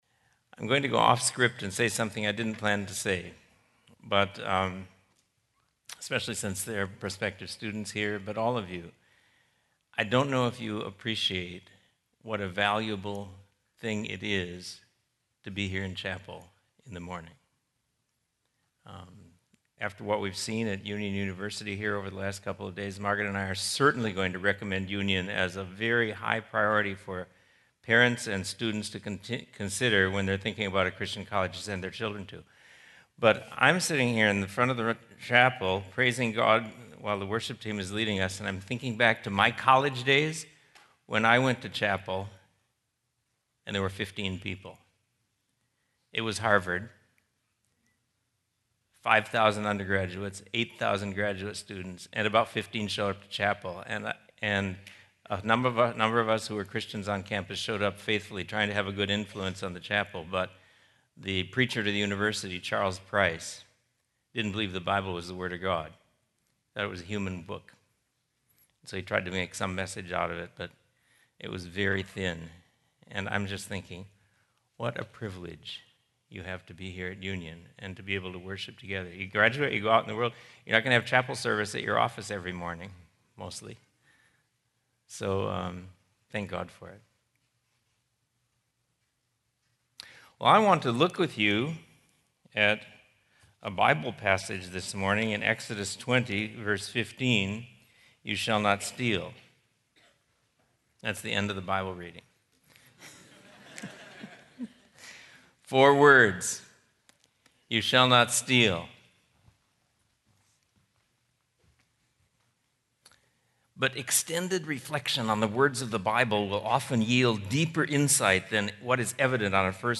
Chapel: Wayne Grudem